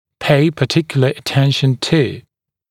[peɪ pə’tɪkjulə ə’tenʃn tuː][пэй пэ’тикйулэ э’тэншн ту:]уделять особое внимание ….